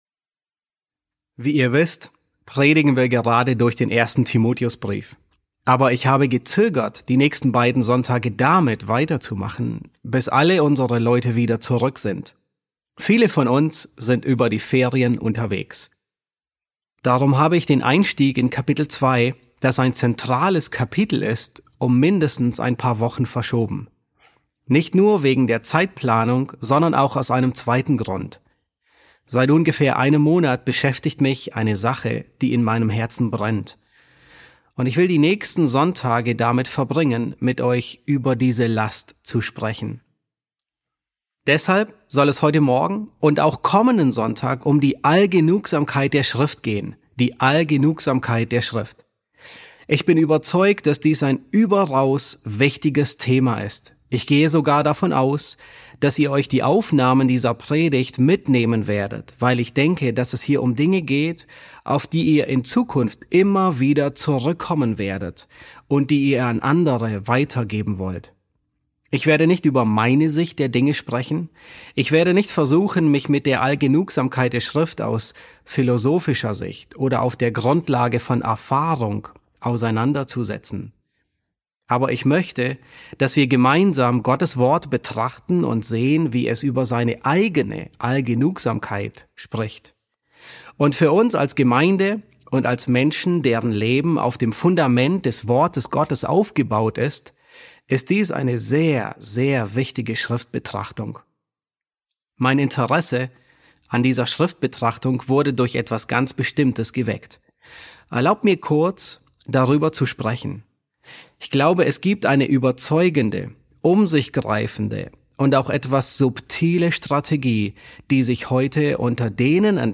E1 S3 | Die Allgenugsamkeit der Schrift, Teil 1 ~ John MacArthur Predigten auf Deutsch Podcast